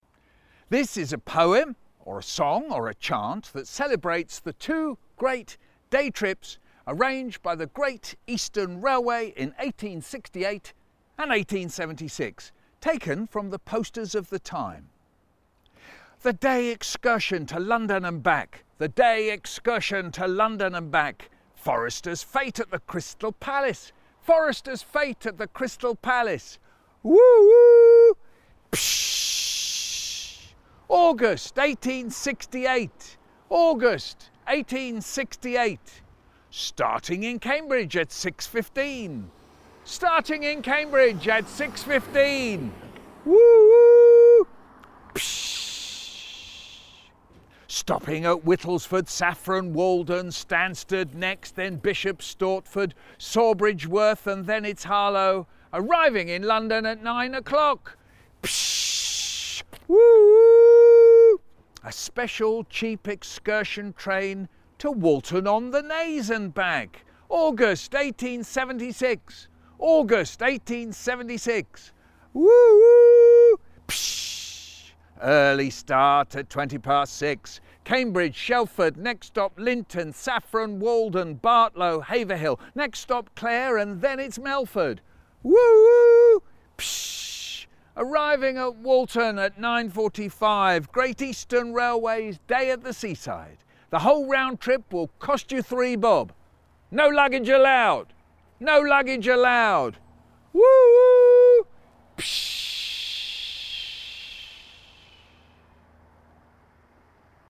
A POEM OR SONG OR CHANTTHAT CELEBRATES THE TWO GREAT DAY TRIPSARRANGED BY THE GREAT EASTERN RAILWAYIN 1868 AND 1876TAKEN FROM THE POSTERS OF THE TIME